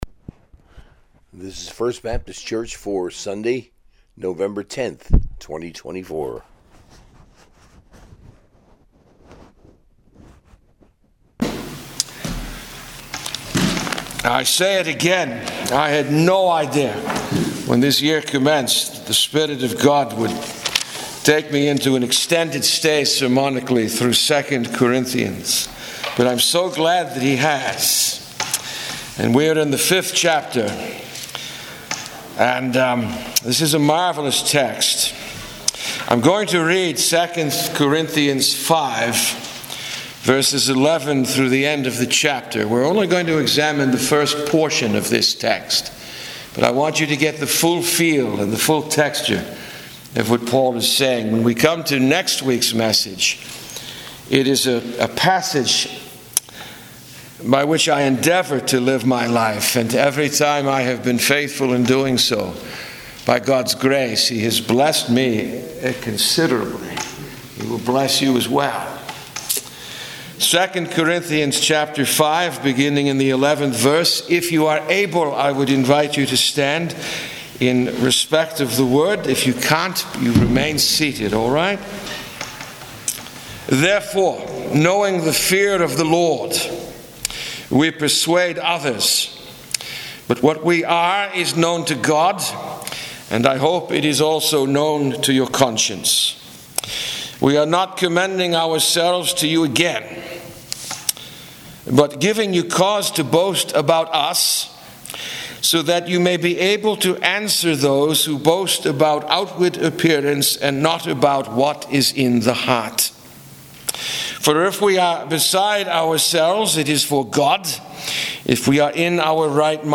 Sunday Sermon, taken from II Corinthians 5:11-End, Part I